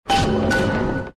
bioshocknotification_24736.mp3